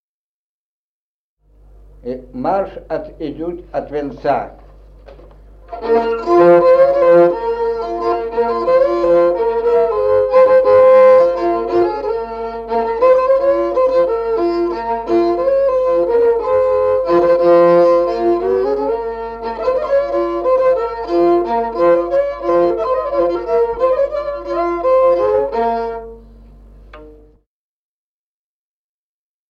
Музыкальный фольклор села Мишковка «Марш, от венца», репертуар скрипача.